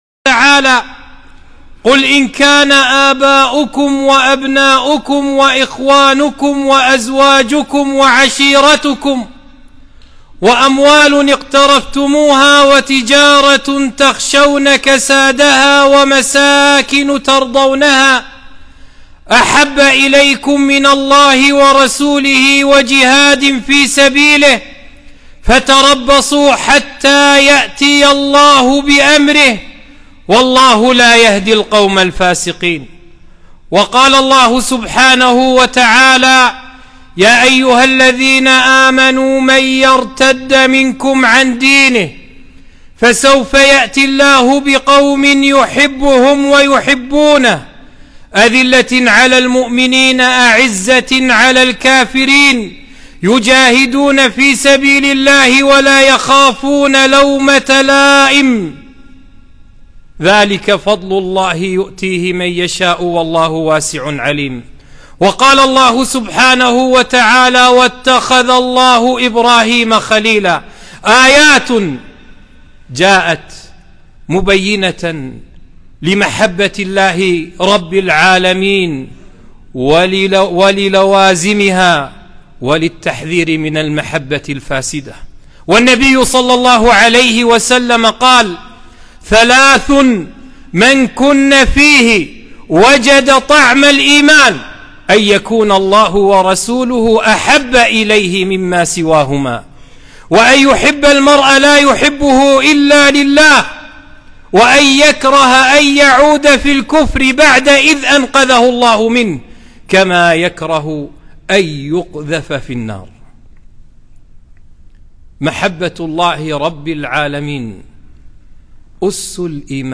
خطبة - محبة الله تعالى